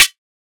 Index of /99Sounds Music Loops/Drum Oneshots/Twilight - Dance Drum Kit/Claps